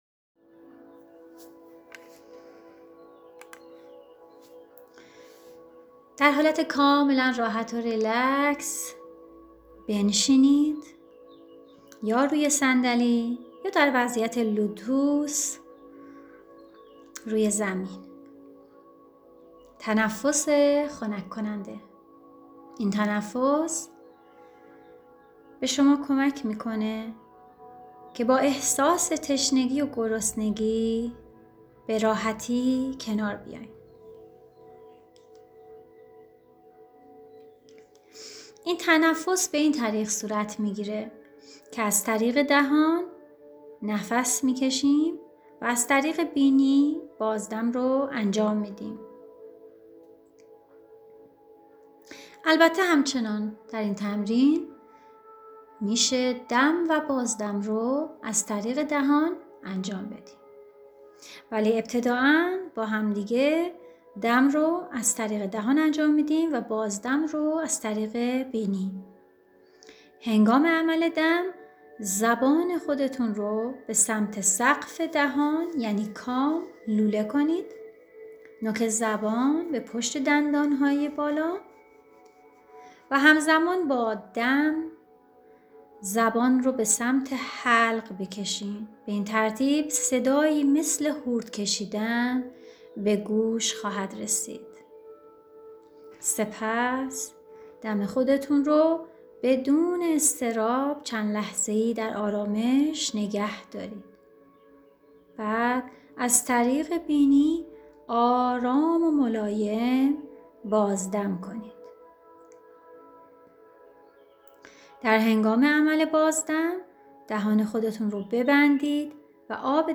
مراقبه تنفسی خنک کننده